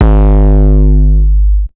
Index of /90_sSampleCDs/Zero-G - Total Drum Bass/Instruments - 1/track26 (Basses)
07 909 Flake G.wav